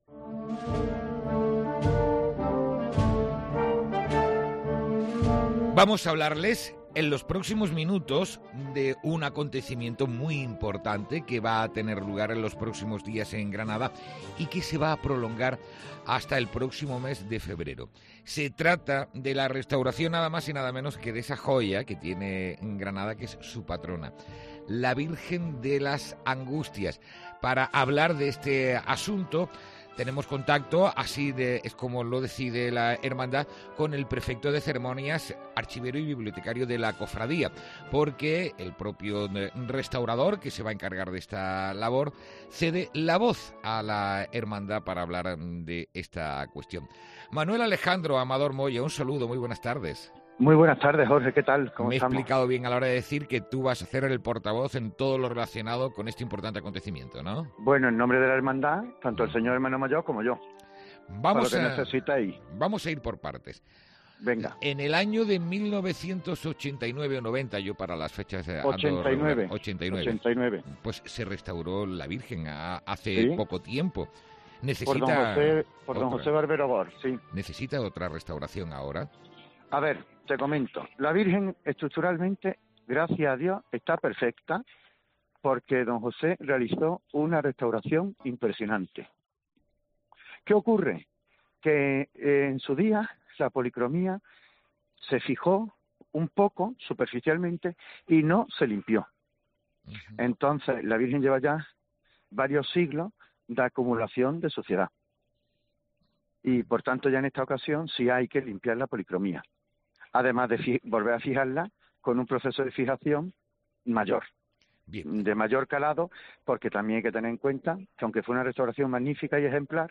ENTREVISTA|| Todos los detalles sobre la restauración de la Virgen de las Angustias